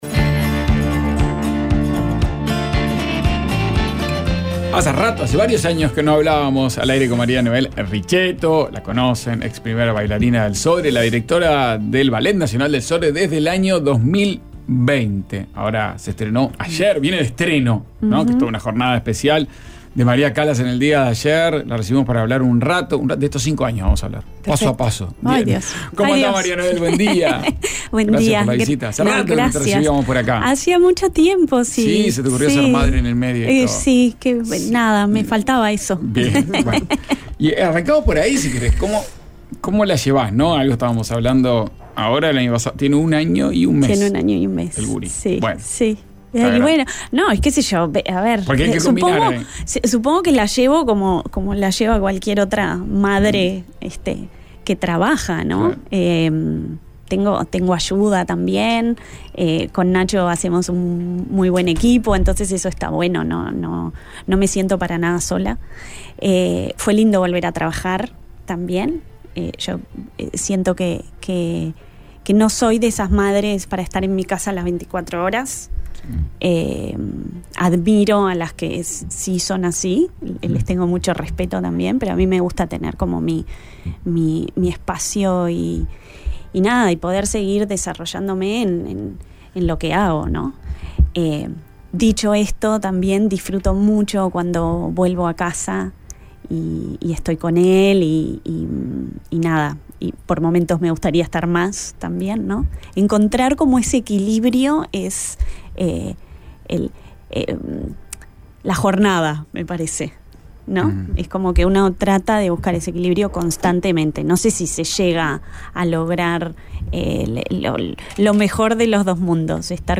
Entrevista con el Comisionado Parlamentario Penitenciario, Juan Miguel Petit, a propósito del informe de su oficina respecto a 2022.